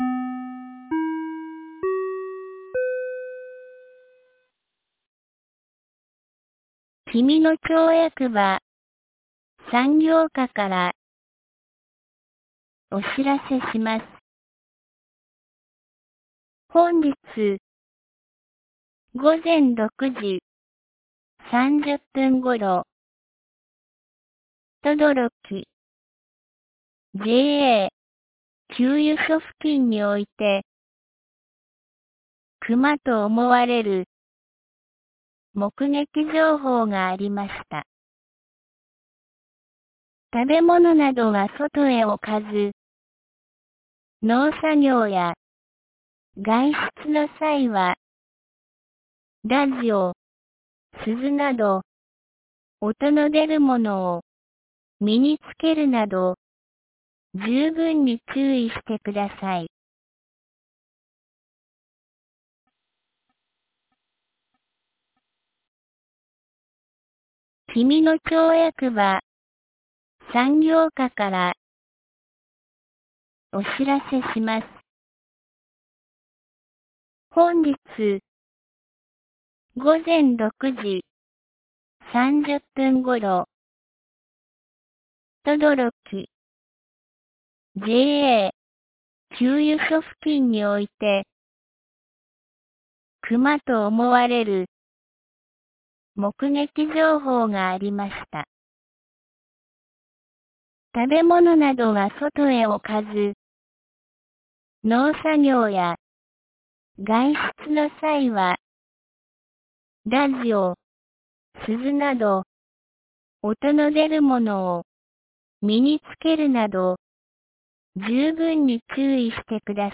2021年05月17日 12時32分に、紀美野町より東野上地区、小川地区、志賀野地区へ放送がありました。